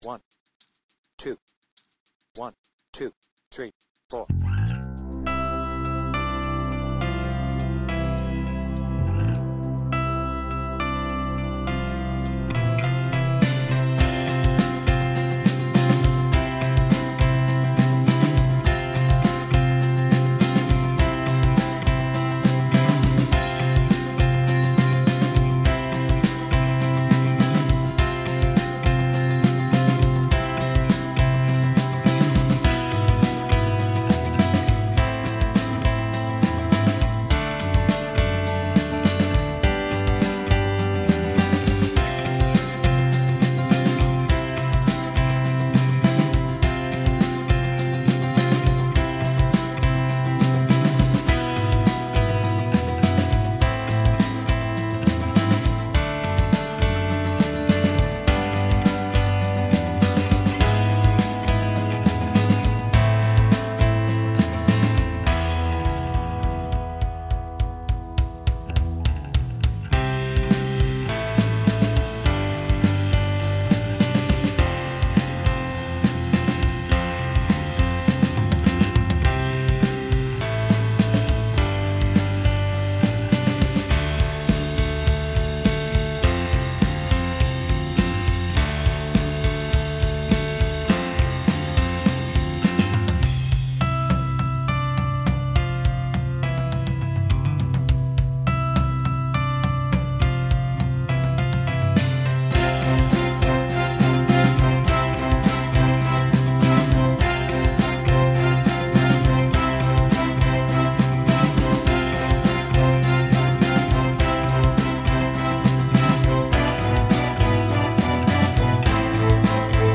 BPM : 106
Tuning : E
With vocals